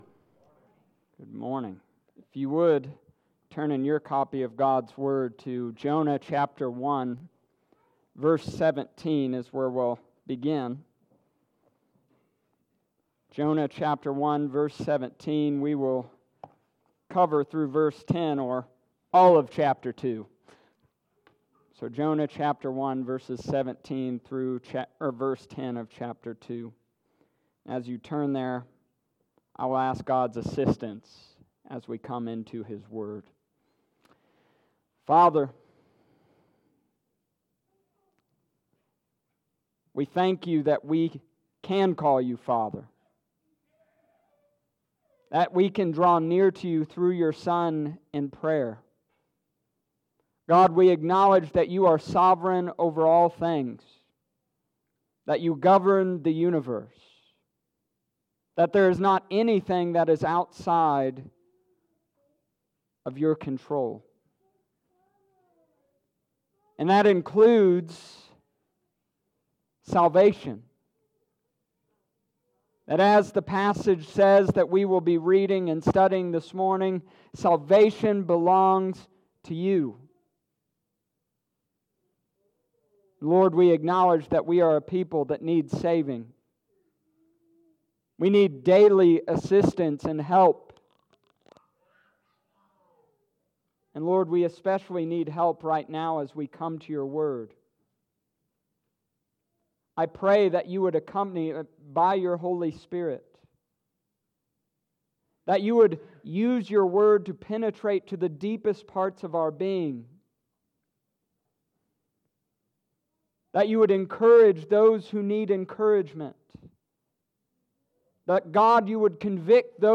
Summary of Sermon